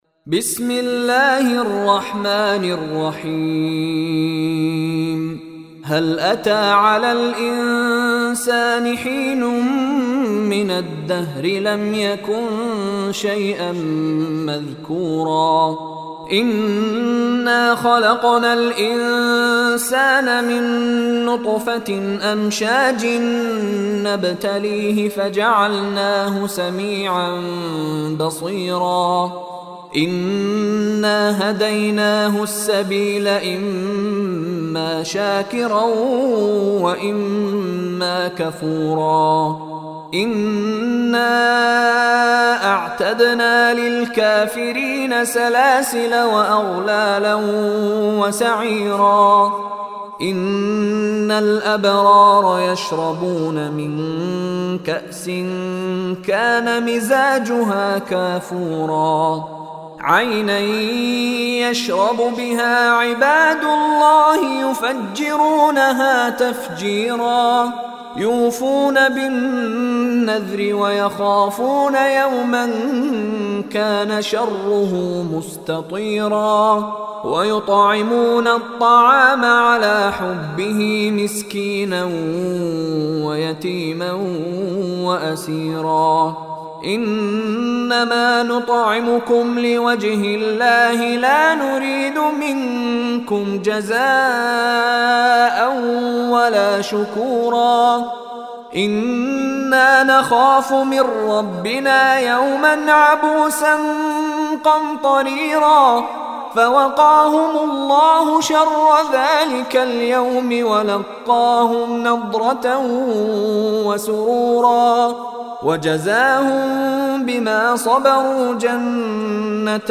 Listen online and download beautiful tilawat / recitation of Surah Al-Insan in the voice of Sheikh Mishary Rashid Alafasy.